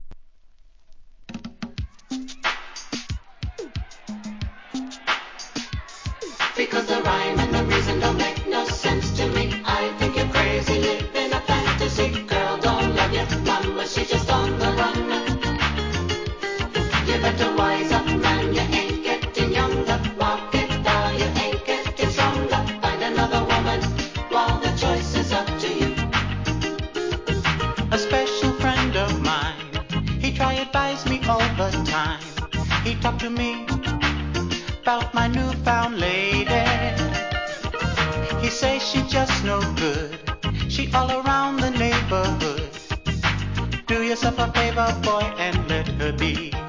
SOUL/FUNK/etc... 店舗 ただいま品切れ中です お気に入りに追加 1987年、美メロSOUL!!